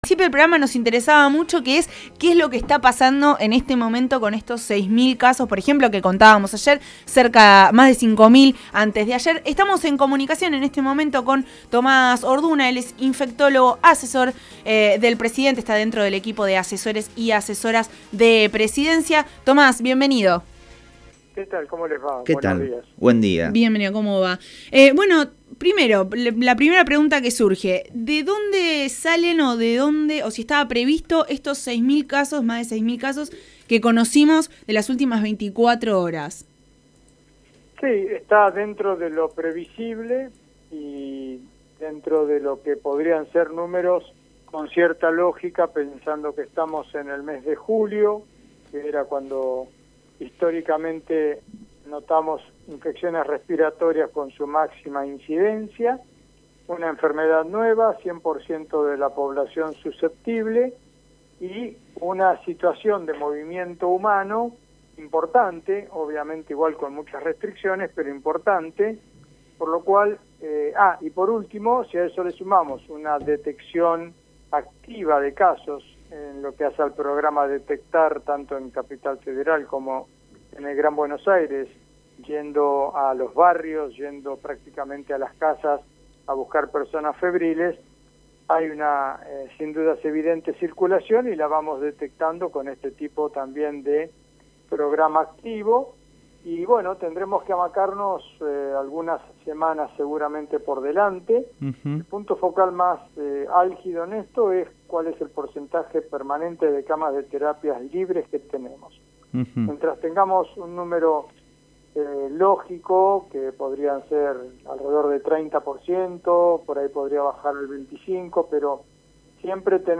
infectólogo